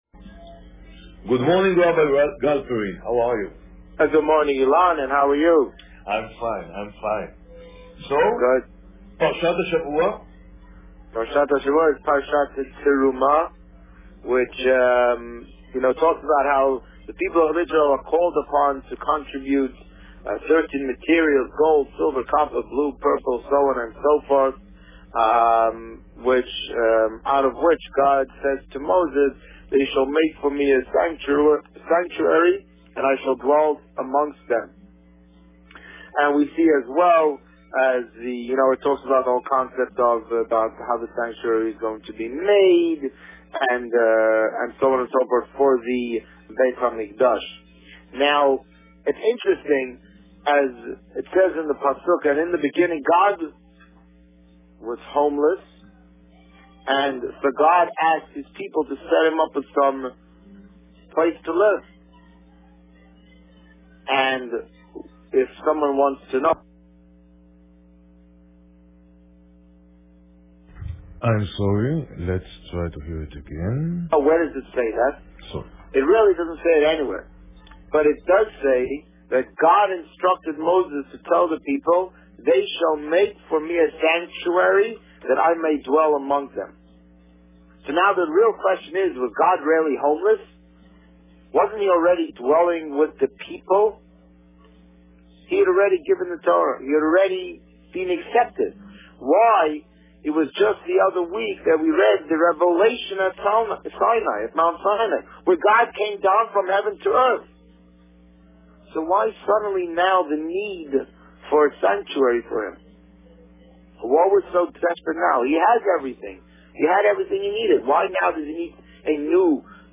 You are here: Visitor Favourites The Rabbi on Radio The Rabbi on Radio Parsha Terumah Published: 30 January 2014 | Written by Administrator This week, the Rabbi spoke about Parsha Terumah. Listen to the interview here .